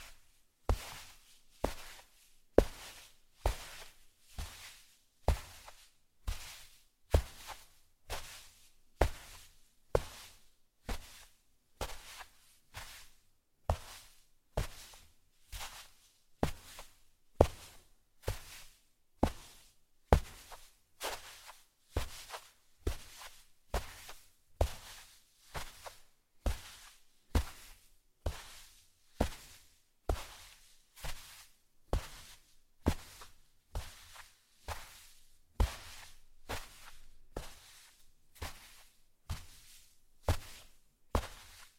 На этой странице собраны разнообразные звуки песка: от шуршания под ногами до шелеста дюн на ветру.
Звук шагов на песке